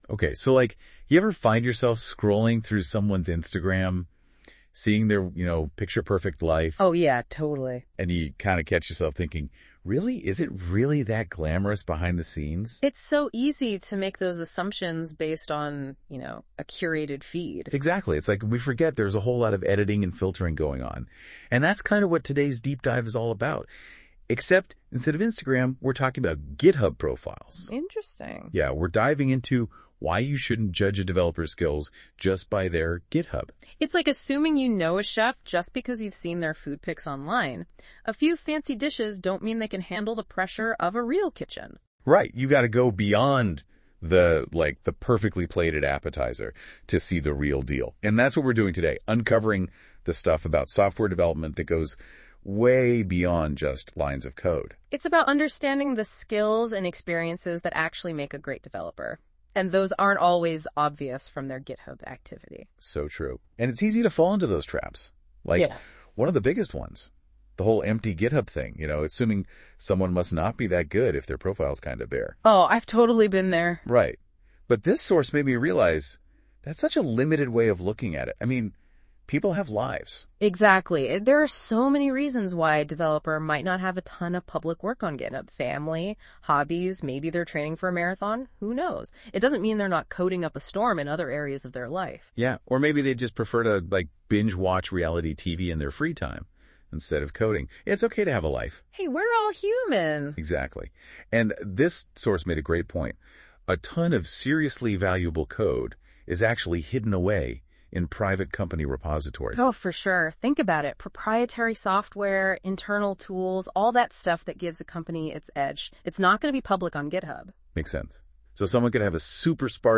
Google Notebooklm is an AI tool that becomes an expert in your notes and helps you, but this is not what has left me speechless, but its tool that transforms your notes into a conversation between two people, for now only available in English language and I show you right now the result.
Google has done an excellent job, please appreciate the tone, the clean audio, and even added a few crutches and the occasional hesitant pause to give it a slight touch of naturalness and realism.